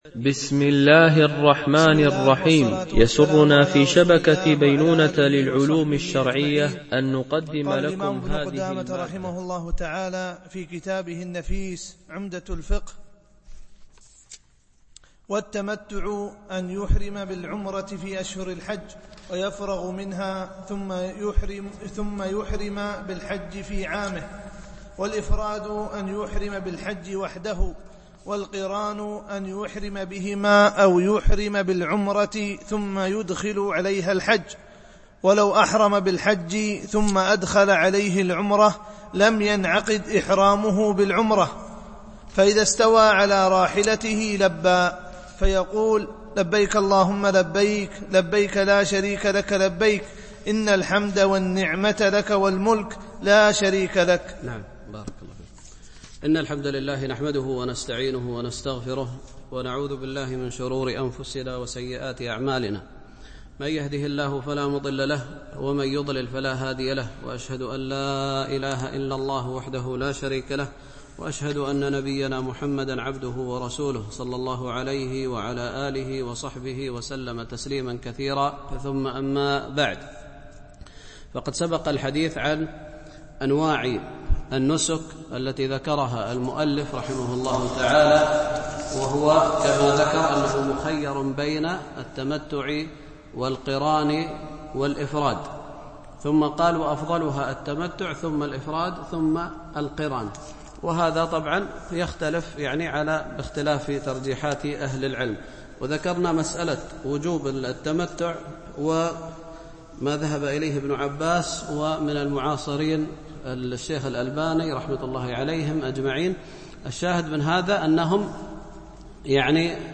شرح عمدة الفقه ـ الدرس56 (كتاب الحج - باب محضورات الاحرام)